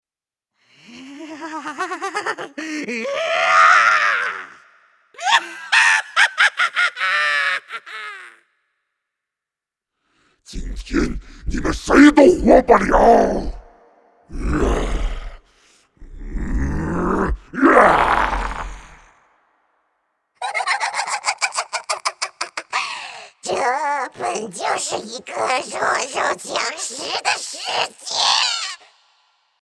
[角色类语音]
比如，用沙哑、低沉的嗓音为怪物配音，通过特殊的发声方式为神秘角色增添空灵之感。